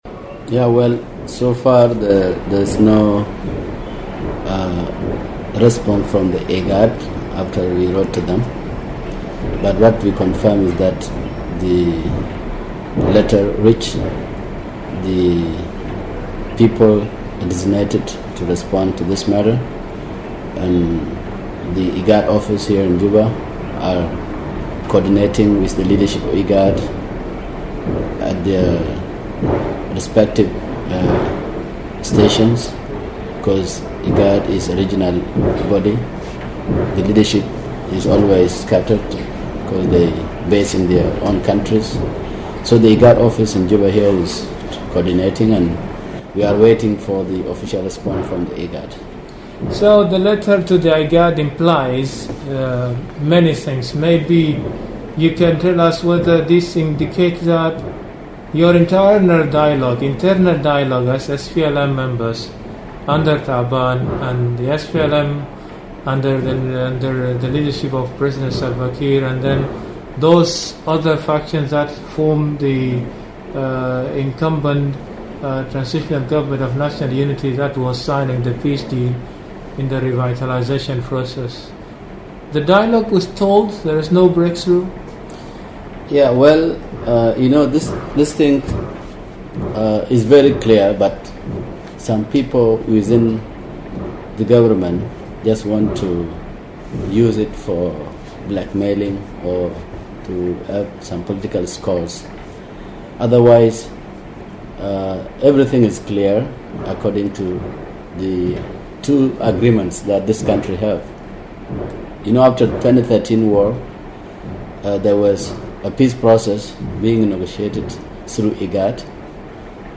The Chief Whip of the SPLM IO in the TNLA Hon Bor Gatwich Teny, spoke exclusively